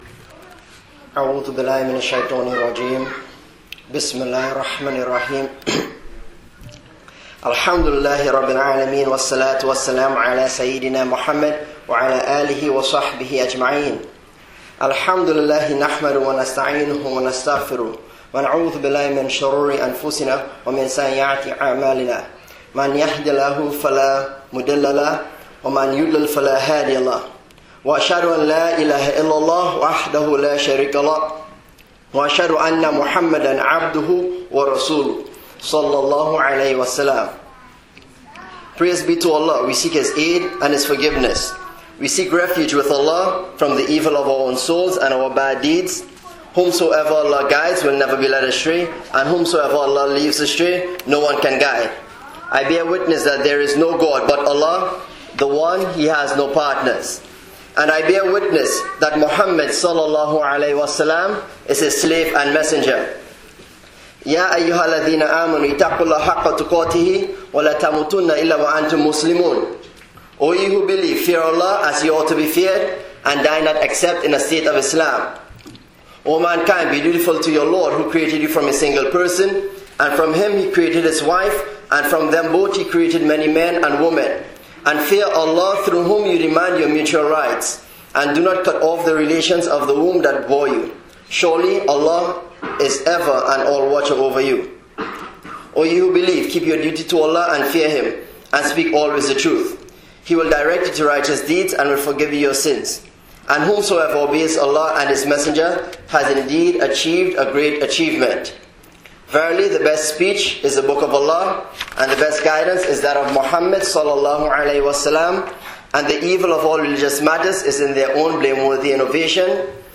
Jumu'ah Khutbah: Gratitude: A Key Part of Faith (Masjid Quba | 8/19/11)